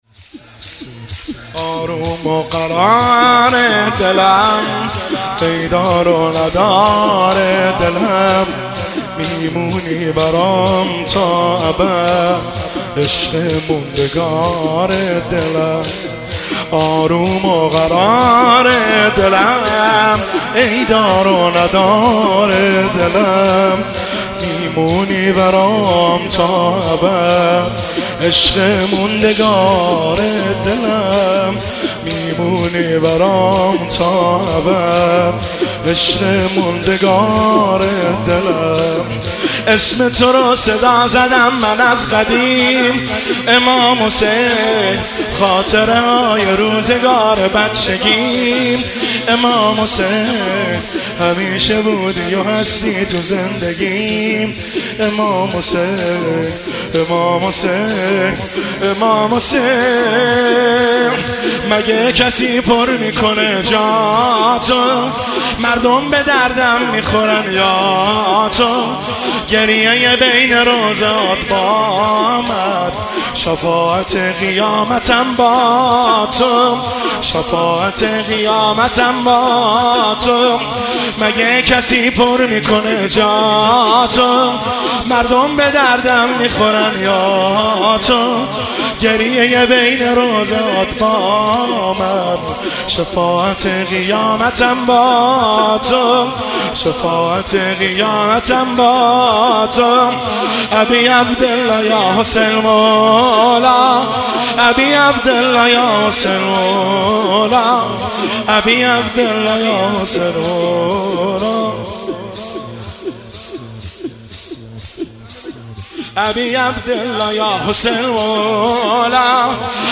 شب اول محرم 1404